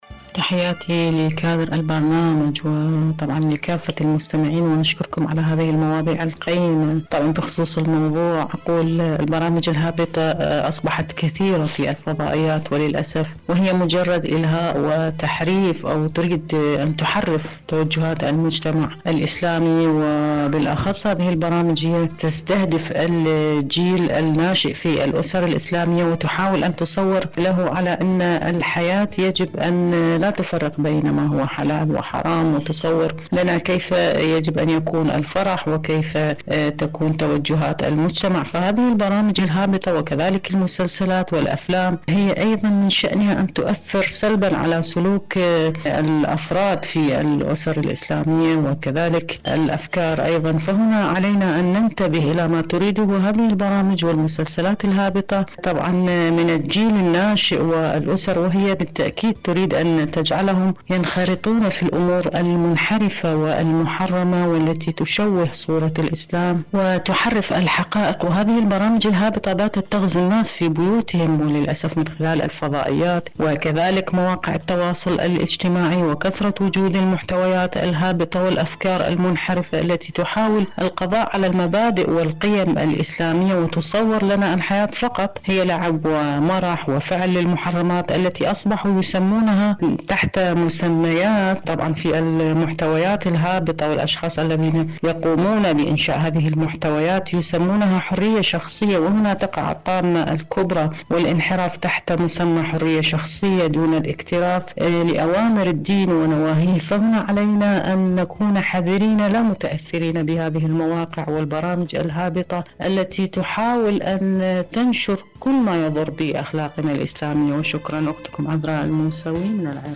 إذاعة طهران- معكم على الهواء